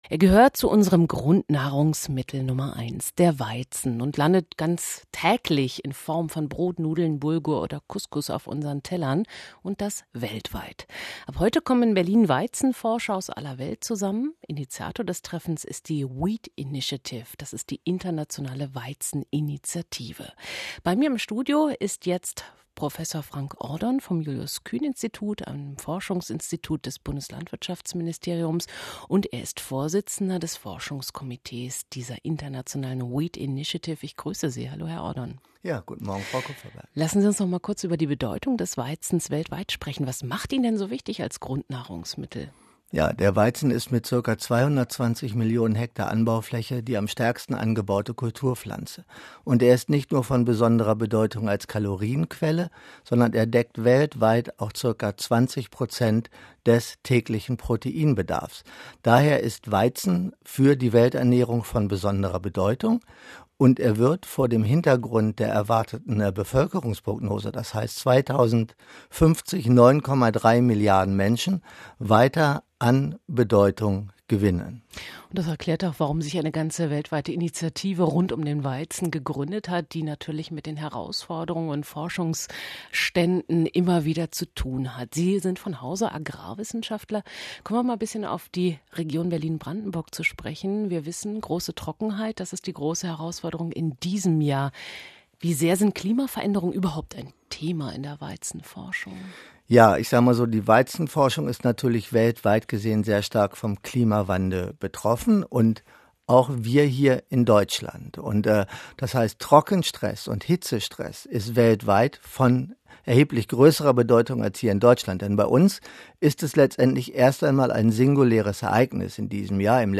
in der Morgensendung des RBB Kulturradiosein Interview zur Bedeutung der Weizenzüchtungsforschungdas als Podcast nachgehört werden kann.